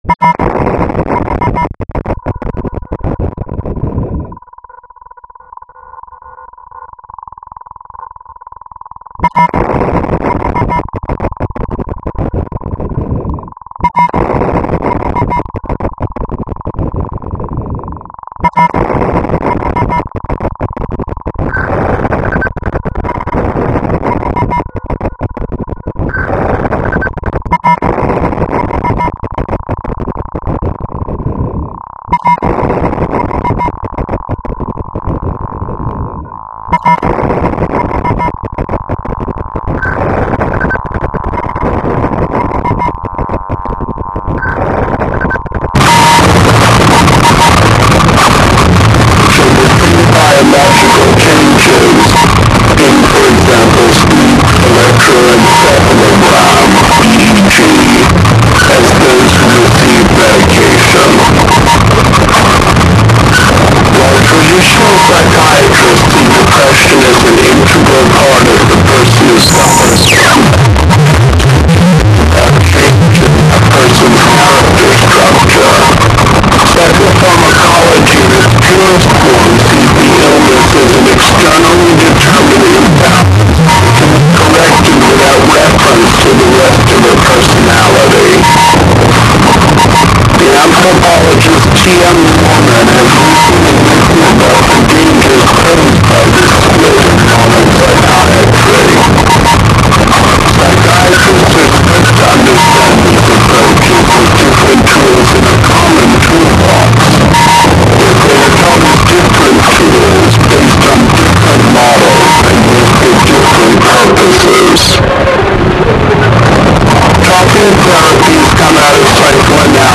This duo is from Maine, USA.